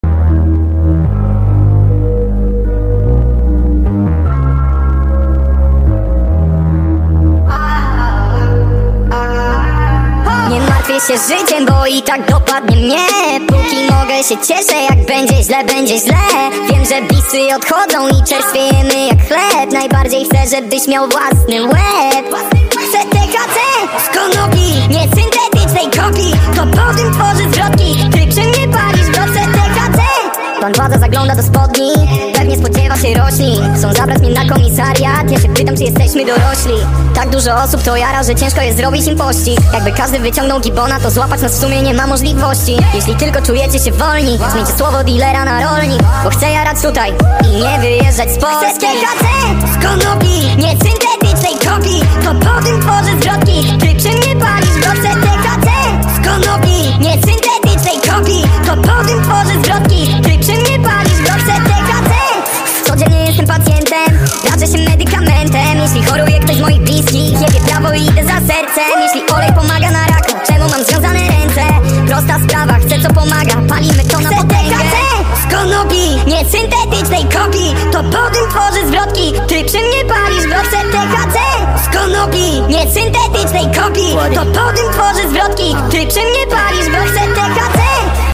(speed up)